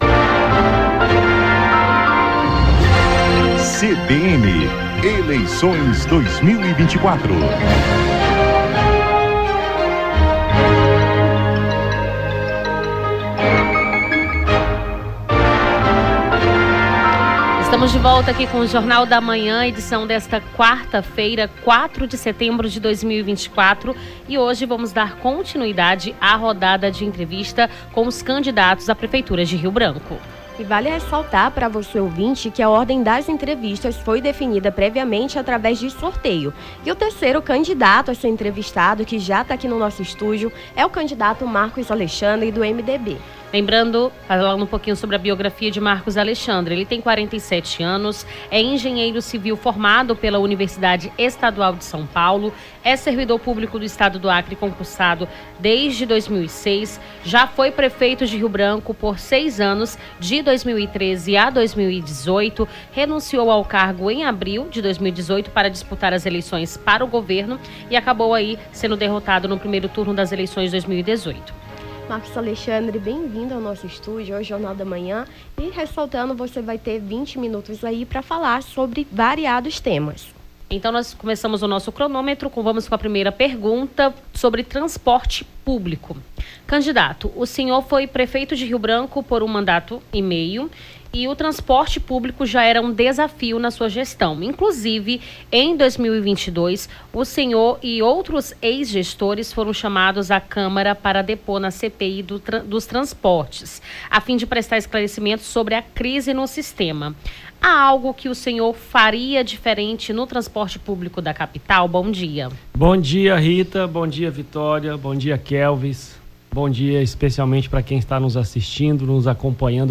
ENTREVISTA CANDIDATO MARCUS ALEXANDRE